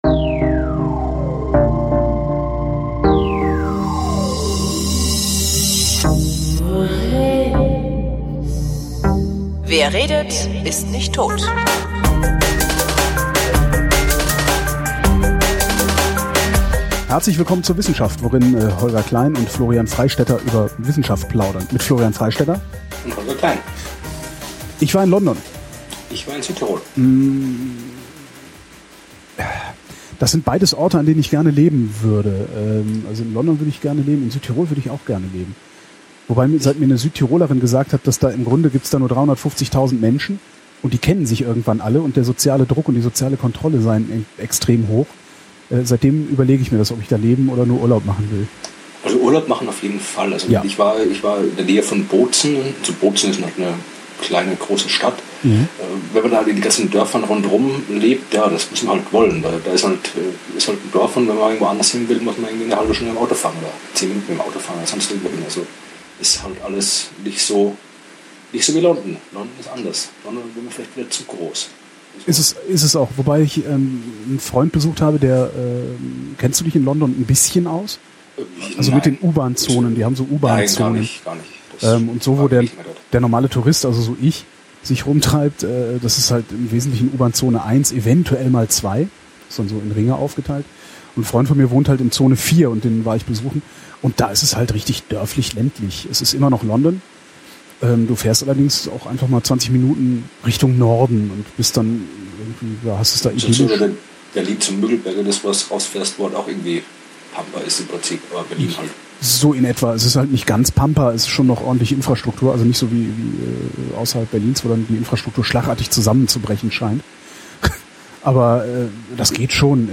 Die ersten zweieinhalb Minuten klingen wirklich sehr schlimm – ich bitte um Verzeihung!